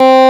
Index of /90_sSampleCDs/USB Soundscan vol.09 - Keyboards Old School [AKAI] 1CD/Partition C/12-FARFISA 3
FARFISA3  C3.wav